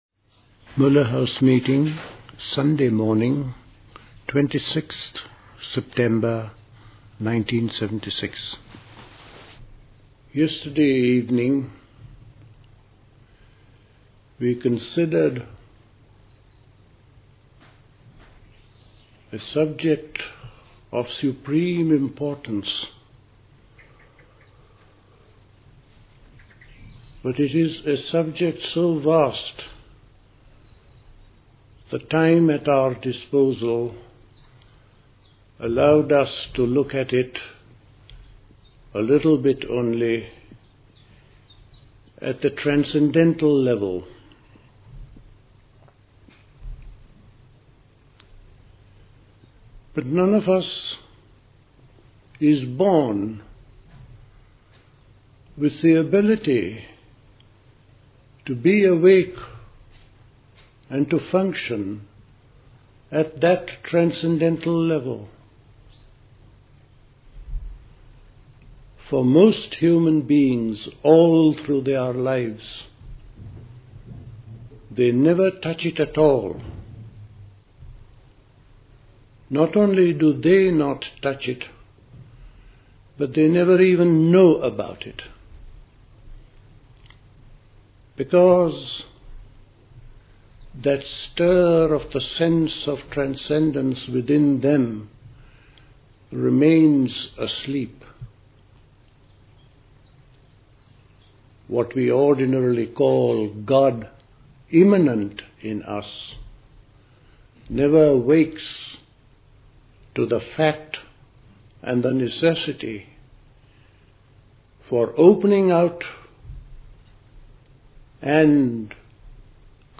A talk
The Elmau School Talks